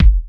keys_20.wav